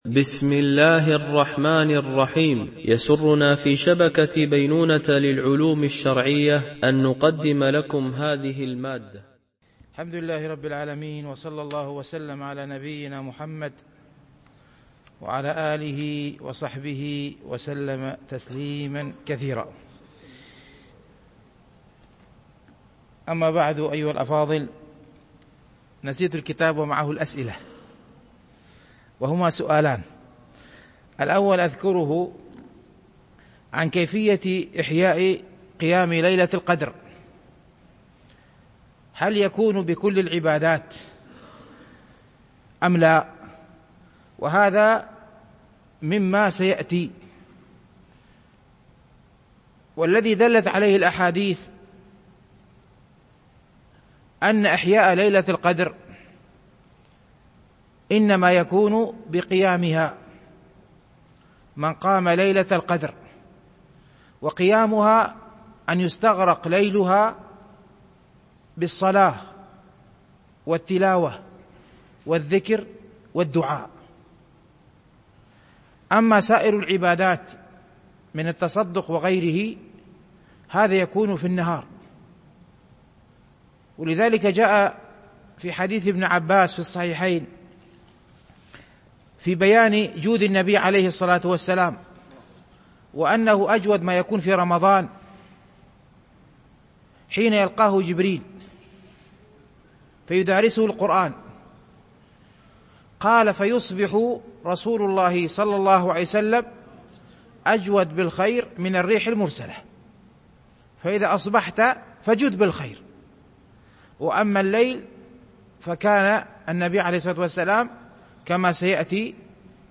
شرح رياض الصالحين – الدرس 309 ( الحديث 1201 - 1205 )